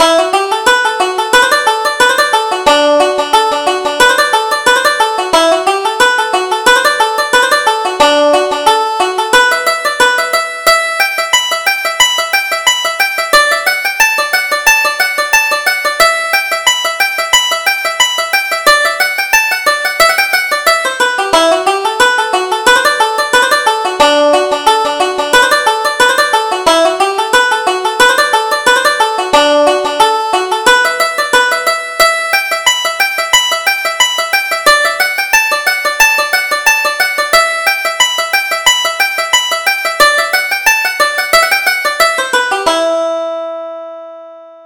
Reel: The Piper's Despair